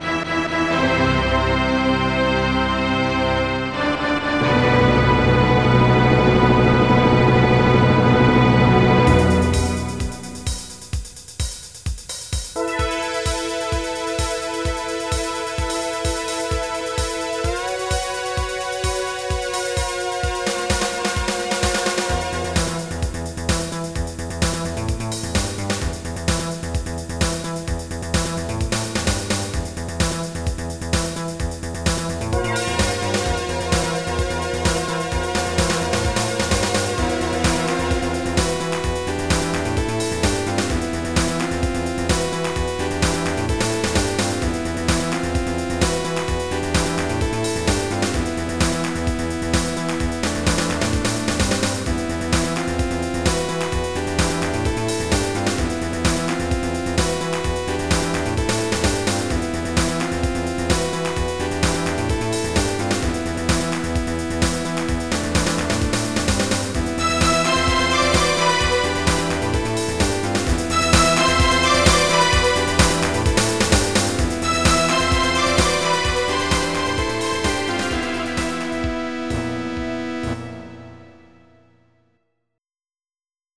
MIDI Music File
Type General MIDI
techno.mp3